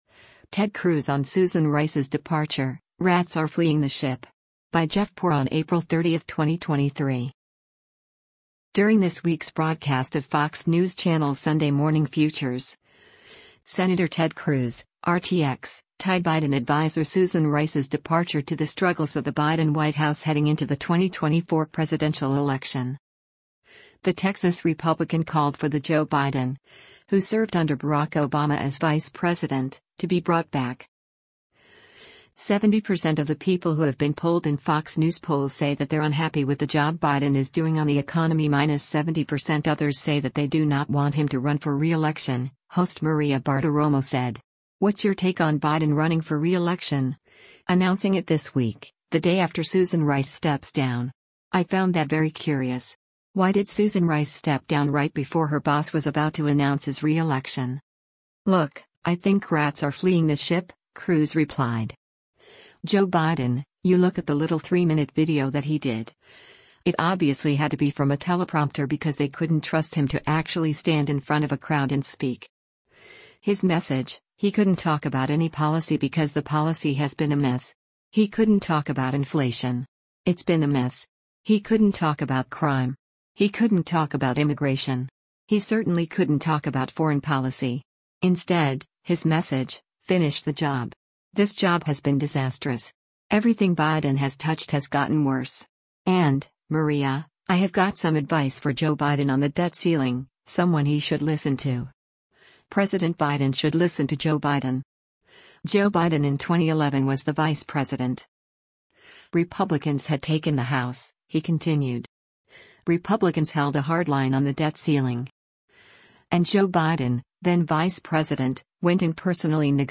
During this week’s broadcast of Fox News Channel’s “Sunday Morning Futures,” Sen. Ted Cruz (R-TX) tied Biden adviser Susan Rice’s departure to the struggles of the Biden White House heading into the 2024 presidential election.